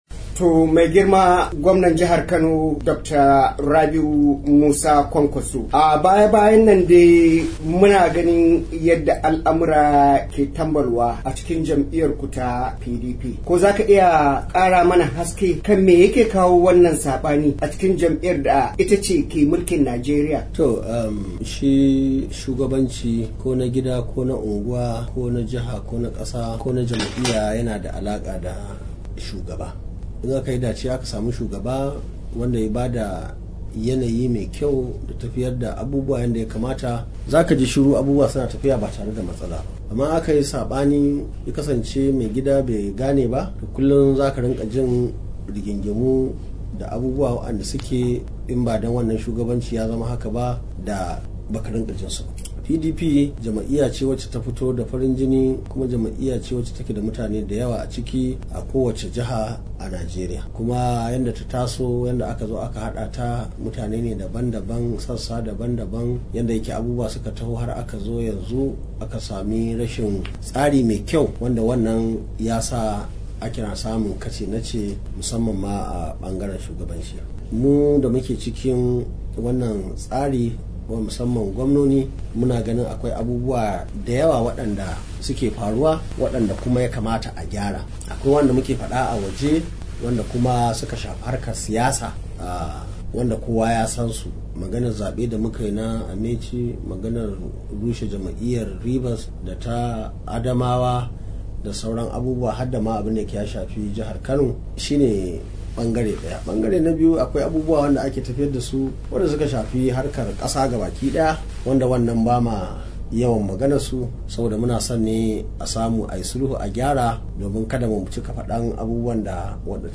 A wata fira da ya yi da Muryar Amurka Gwamnan Kano ya nemi shugabancin jam'iyyarsa ta PDP ya sake salon yadda yake gudanar da harkokin jam'iyyar.